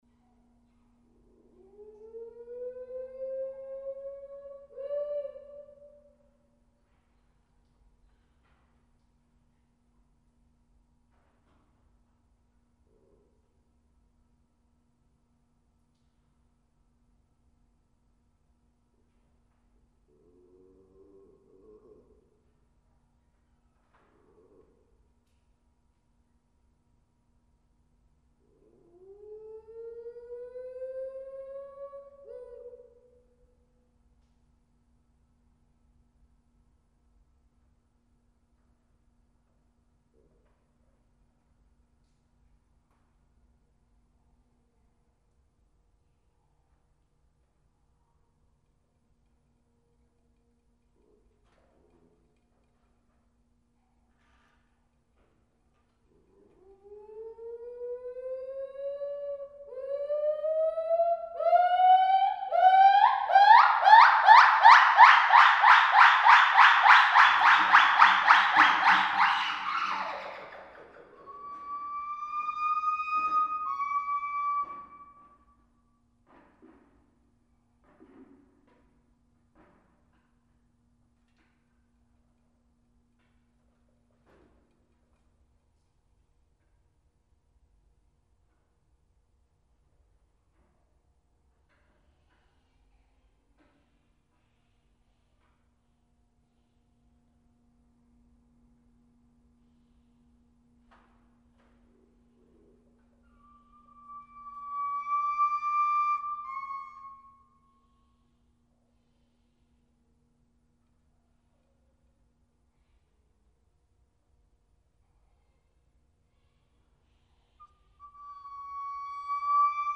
They vocalized quite reliably each morning 6-8 AM before going on exhibit.  Their spectacular duet is deafeningly loud in person and is nearly as impressive visually as it is sonically, as the climax of Indah's part is accompanied by a frenetic, body-shaking display.
Here is a complete duet (over nine minutes).  It is initiated by Indah ("whooo"); Benny first responds (high two-note call) after Indah's first climax.
White-Cheeked-Gibbons-complete.mp3